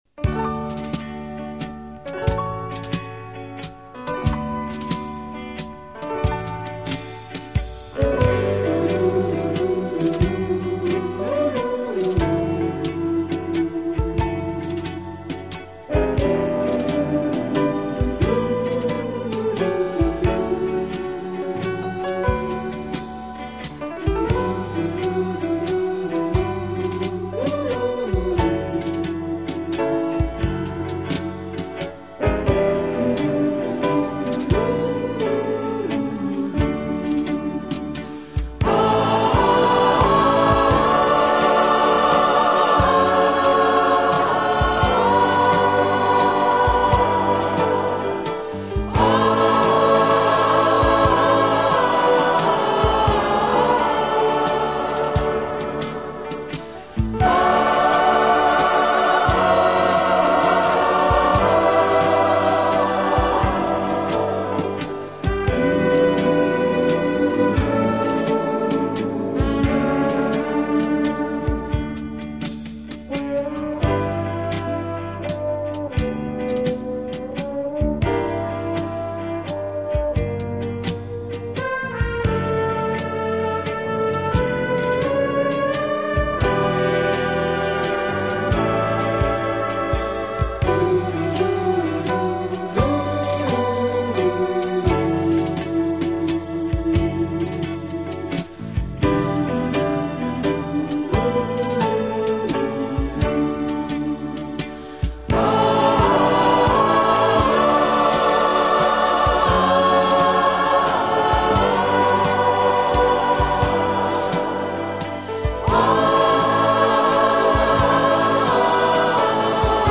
Эта музыка, конечно, Вам знакома. Просто этот вальс стал в последнее время одним из самы моих любимых.